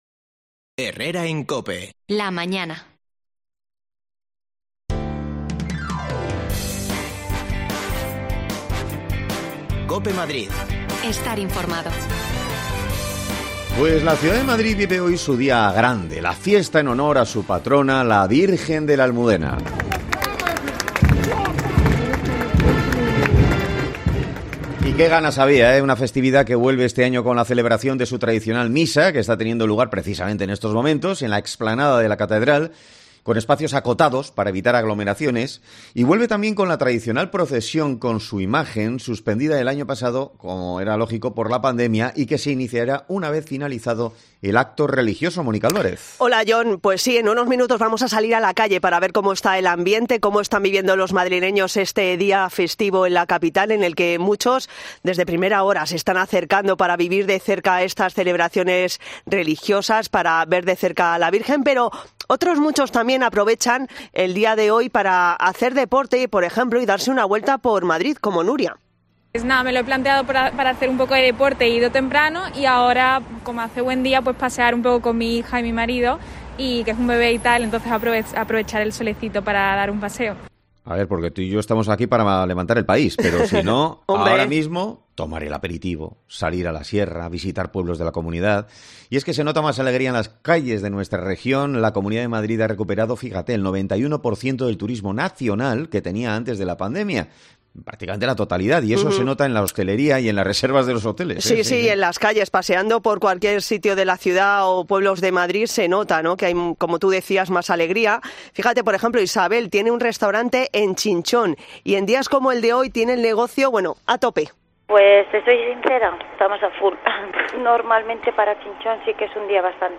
AUDIO: Salimos a la calle para comprobar cómo están viviendo los madrileños esta festividad de la Almudena